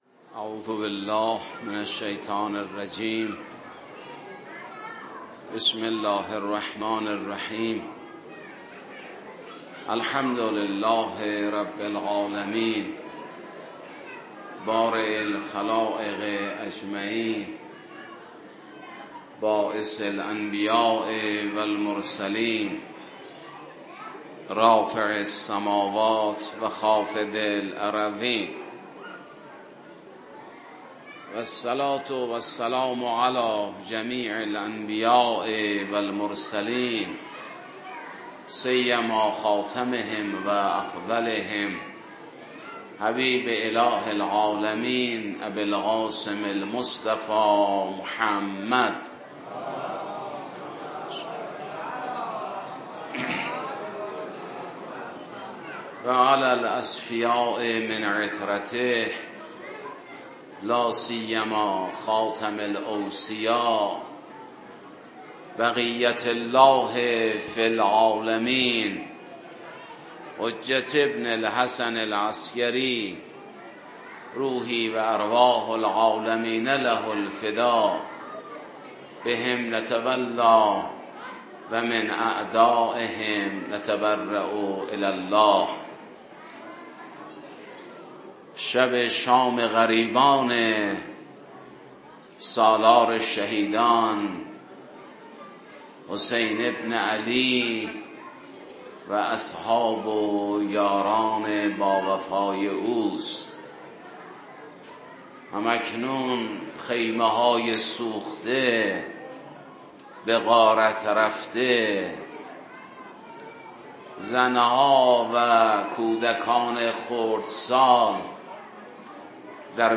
در شب شام غریبان حسینی
سخنانی در جمع عزاداران حسینی